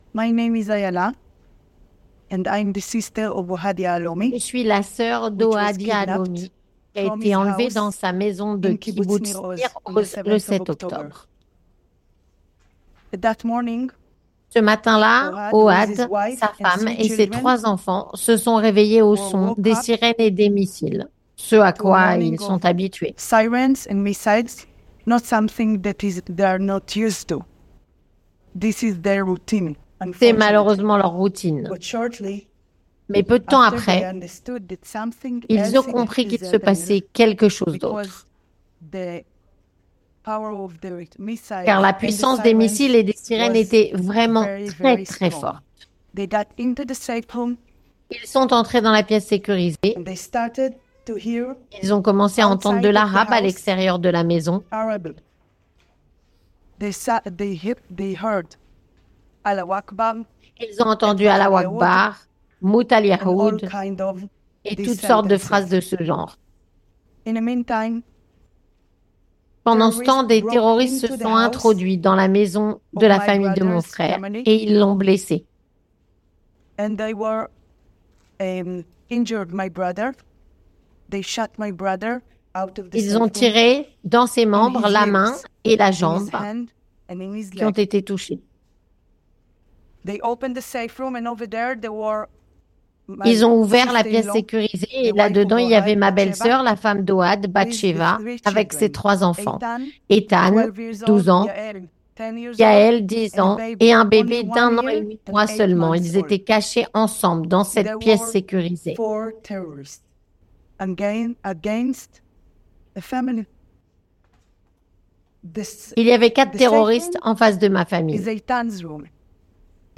Traduit et doublé